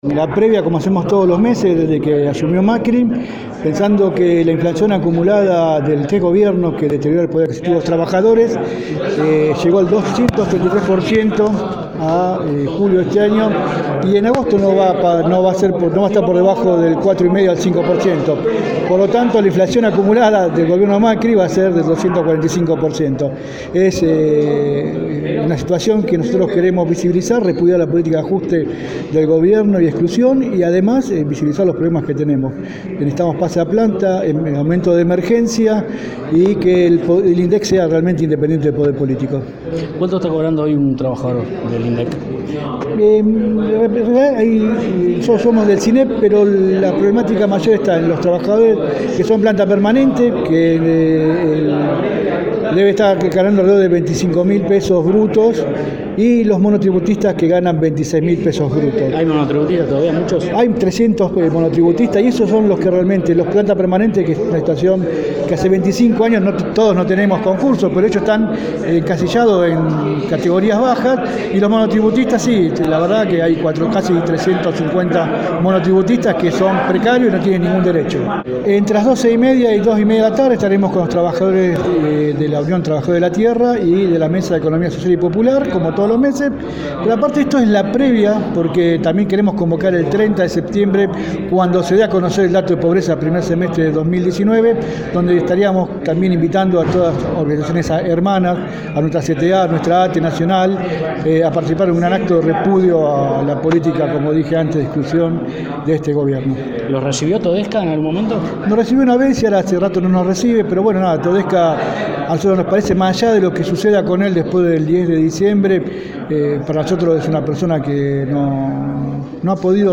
en dialogo con este medio.